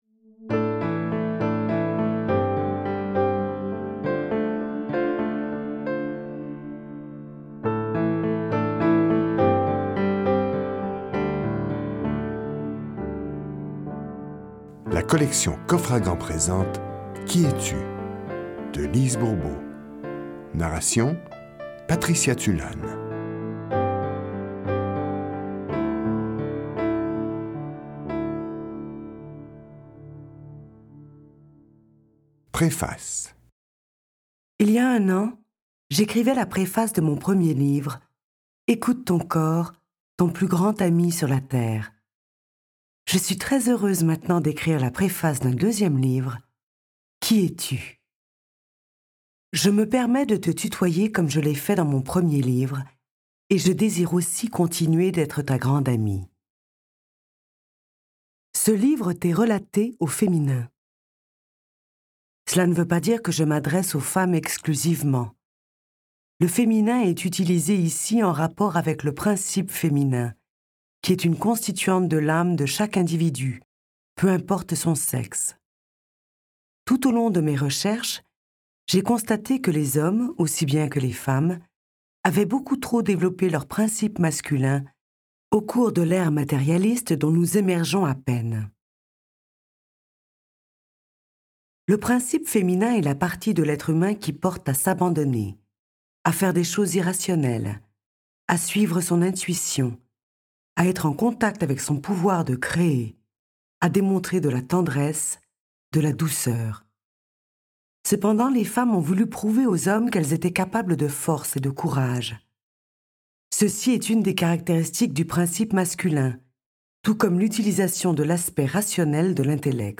Extrait gratuit - Qui es-tu? de Lise BOURBEAU